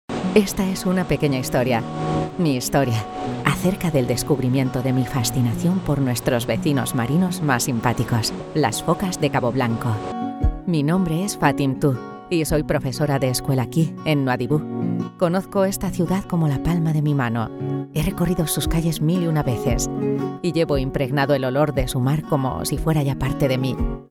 Naturelle, Polyvalente, Profonde, Accessible, Chaude
Vidéo explicative